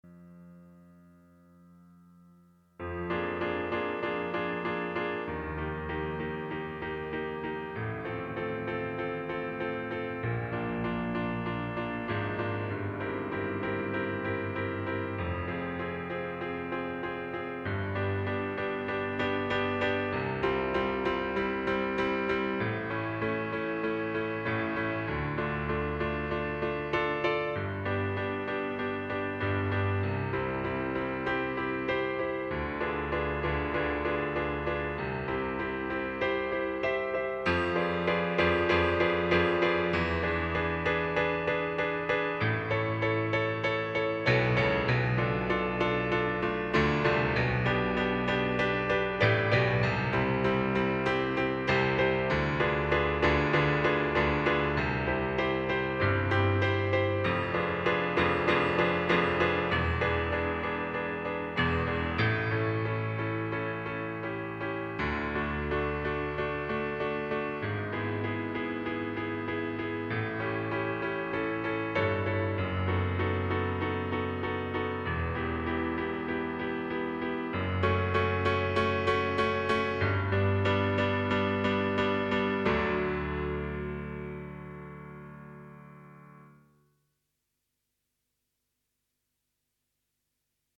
minus solo